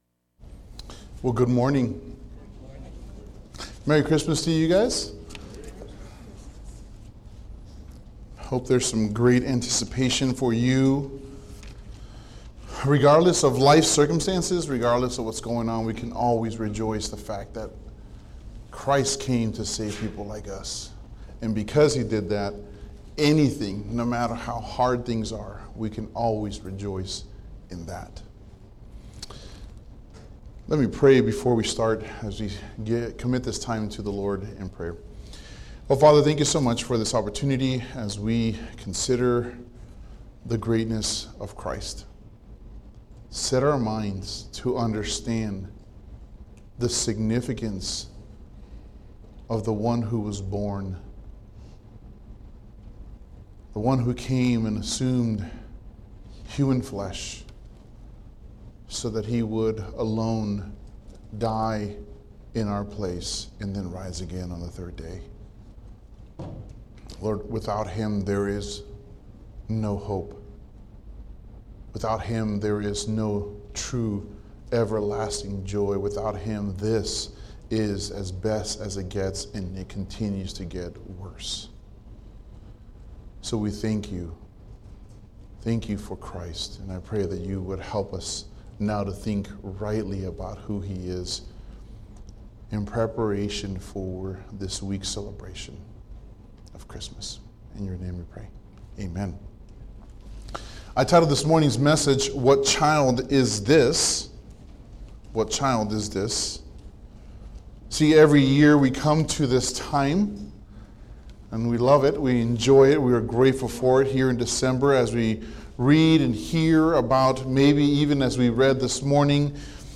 The opening comments did not get recorded, so the audio picks up a few moments into the beginning of the sermon.